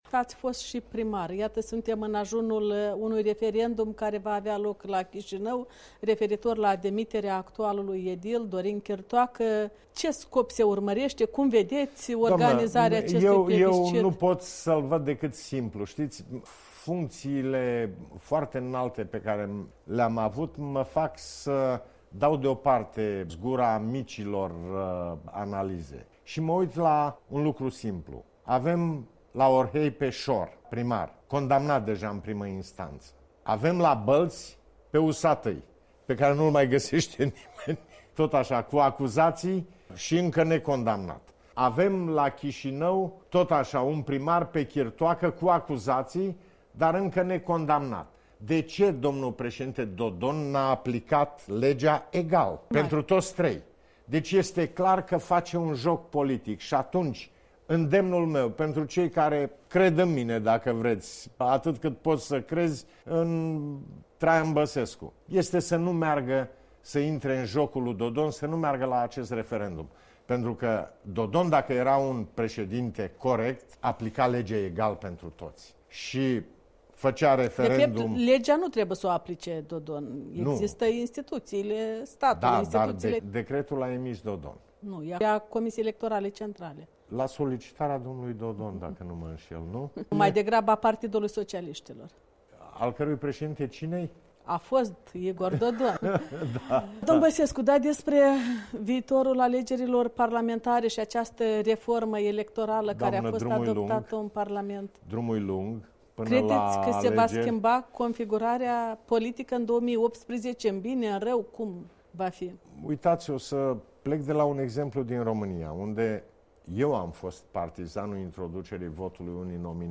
Interviu Traian Băsescu, partea a 2-a